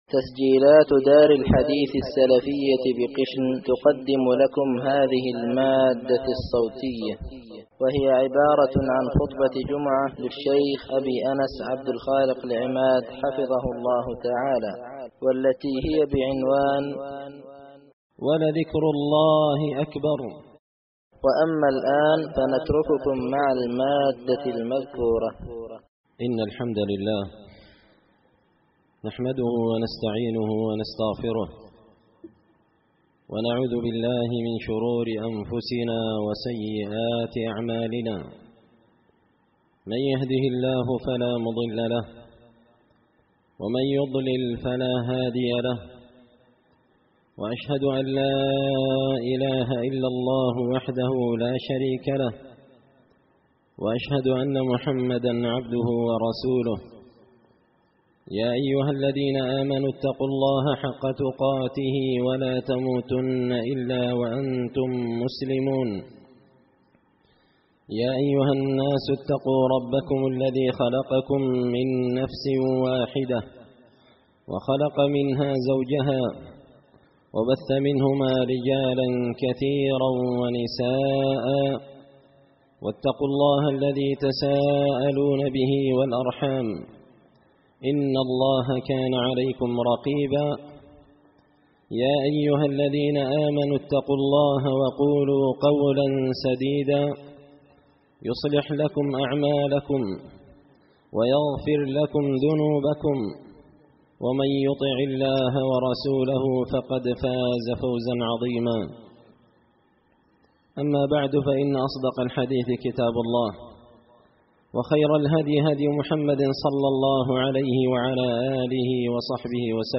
خطبة جمعة بعنوان – ولذكر الله أكبر
دار الحديث بمسجد الفرقان ـ قشن ـ المهرة ـ اليمن
خطبة_جمعة_بعنوان_ولذكر_الله_أكبر_6ذو_الحجة1442هـ_.mp3